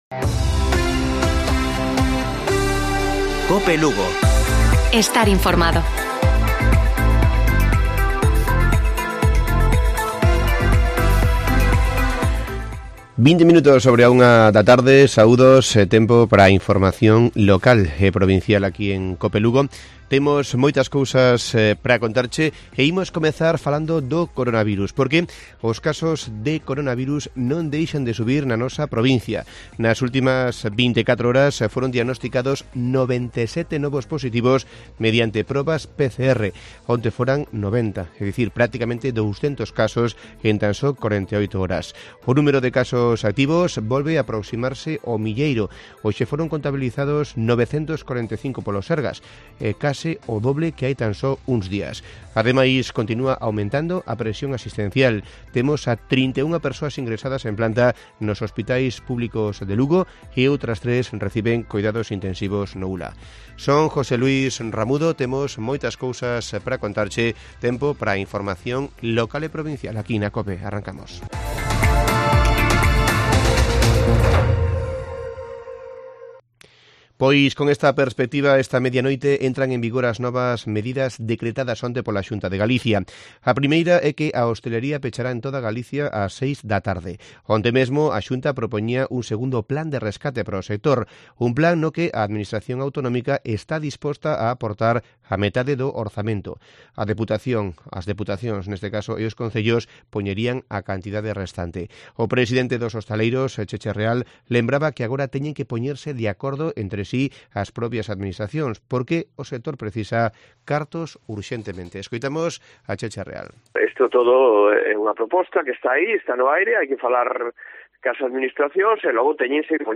Informativo Provincial de Cope Lugo. 14 de enero. 13:20 horas